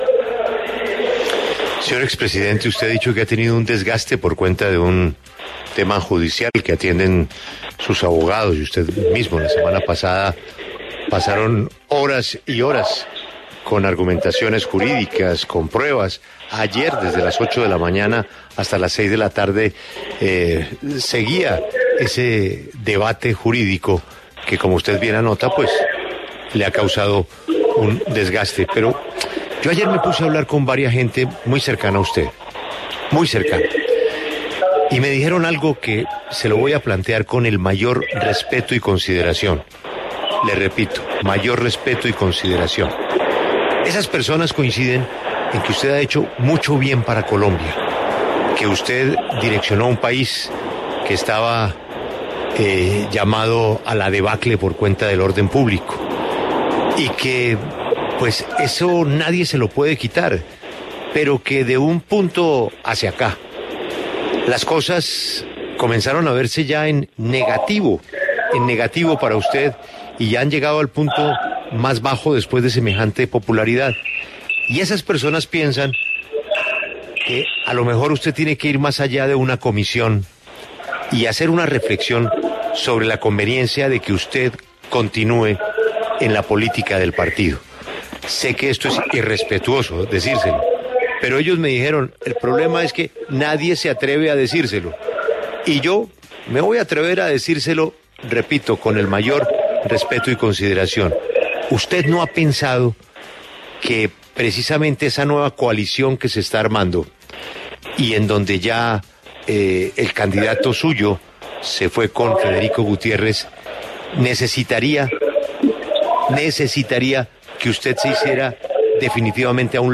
El expresidente de Colombia Álvaro Uribe Vélez habló con La W Radio acerca de las conclusiones que salieron de la reunión que sostuvo el Centro Democrático, convocada por el exmandatario, para tratar temas referentes con lo ocurrido en las elecciones del 13 de marzo.
Julio Sánchez Cristo, director de La W, le preguntó si no ha pensado en hacerse a un lado en este camino electoral y Uribe respondió que él sabe hacer política sin estorbar y que defenderá la democracia toda la vida.